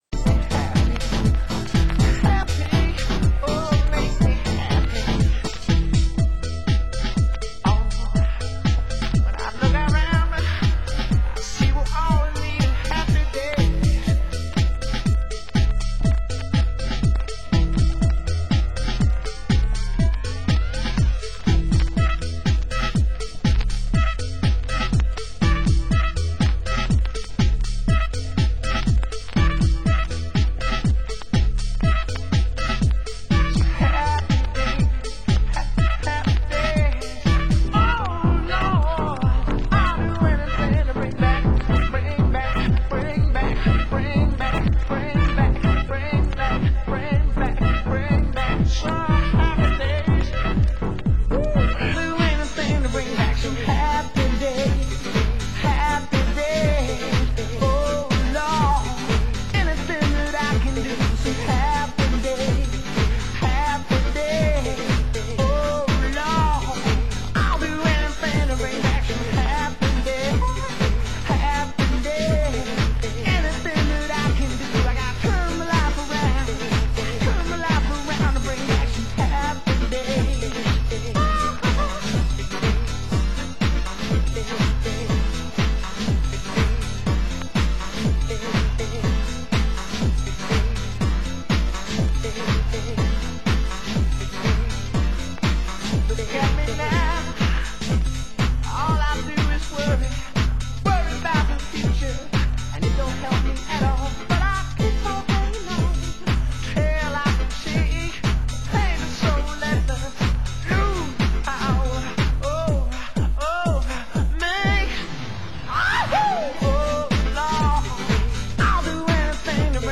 Genre UK House